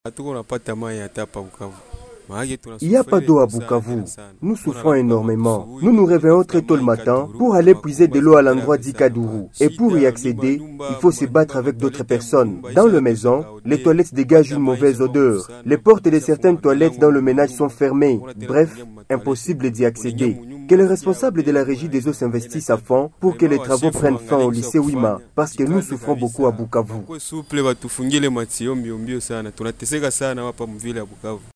Les habitants en appellent à un travail urgent et bien fait de la REGIDESO pour rétablir la desserte en eau dans la ville de Bukavu. Ecoutons un habitant de Bukavu.